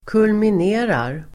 Ladda ner uttalet
Uttal: [kulmin'e:rar]